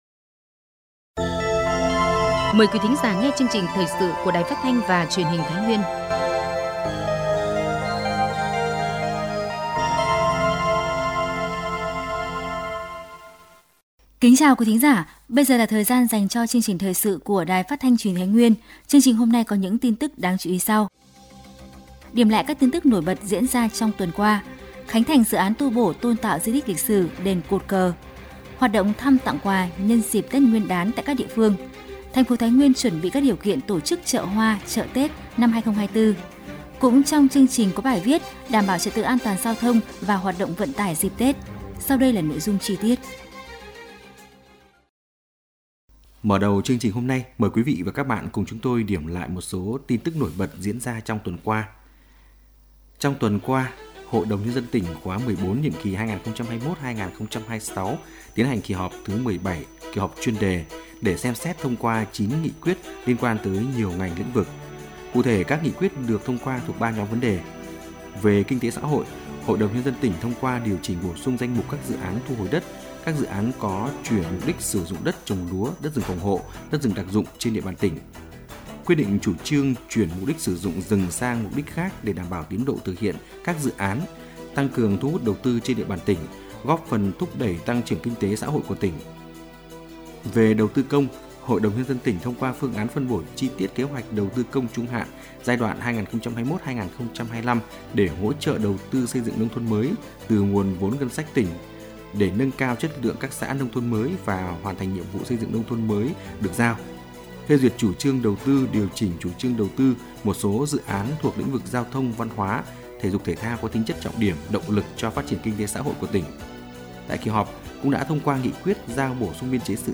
Thời sự tổng hợp Thái Nguyên ngày 04/02/2024